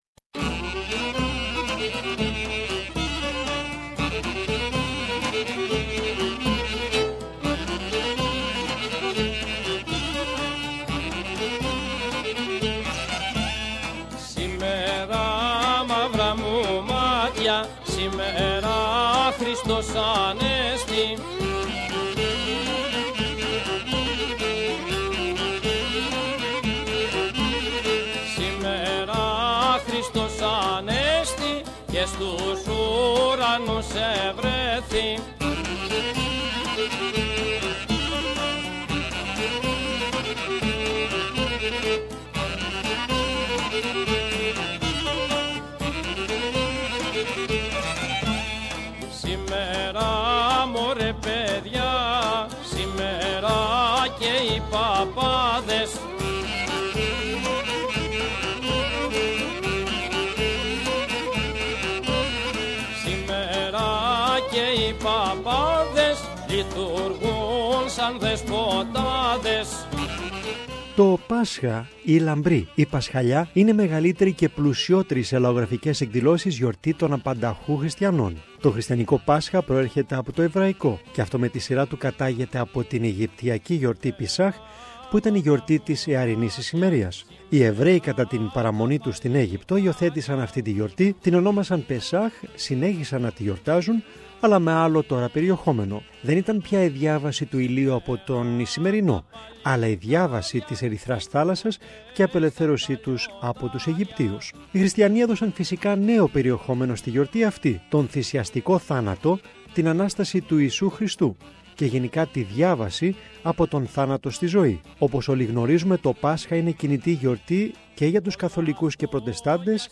From Macedonia in the north to Crete in the south and the Ionian in the west to Cyprus in the east, the customs of the Greeks during Easter are abundant and unique. This report showcases some of the most noteworthy customs and traditions, making Greek Orthodox Easter a must see for any visitor in the homeland!